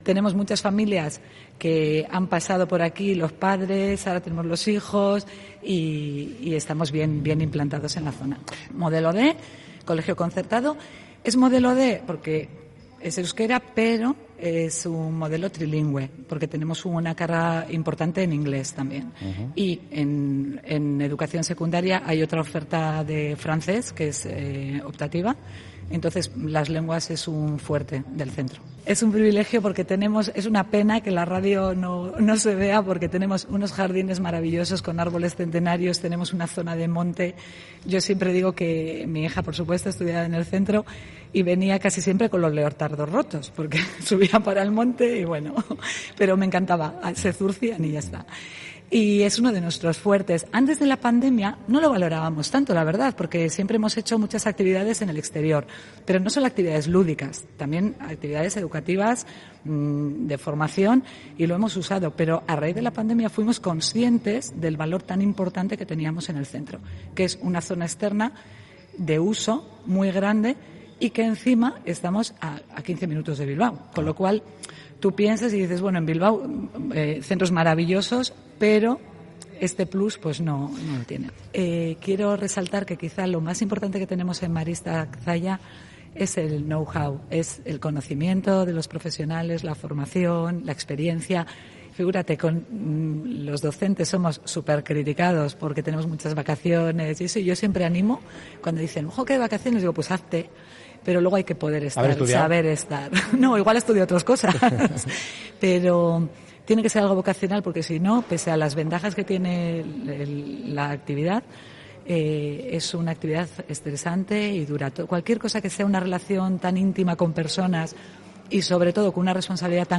Hoy, ese mismo salón de actos ha acogido a Onda Vasca, en el programa especial que hemos realizado en directo desde este centro.